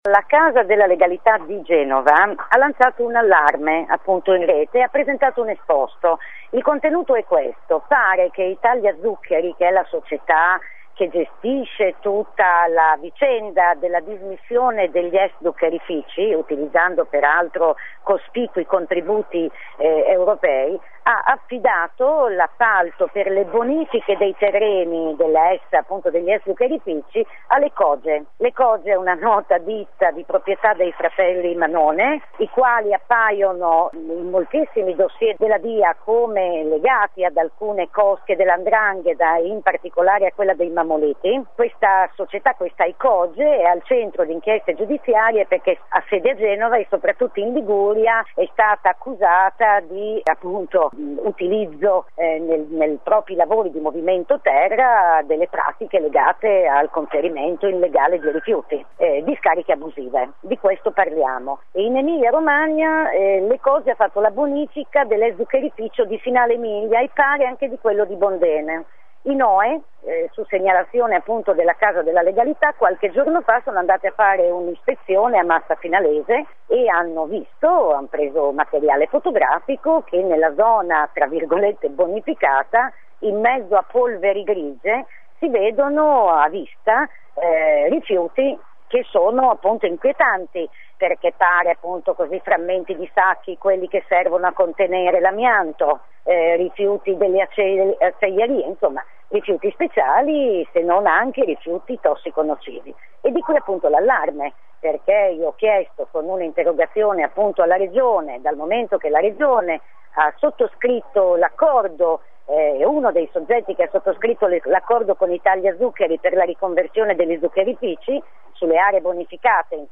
Monica Donini ha presentato un’interrogazione che chiede alla Giunta, firmataria dei protocolli di riconversione degli zuccherifici, di fare dei controlli. Così ai nostri microfoni ricostruisce la vicenda.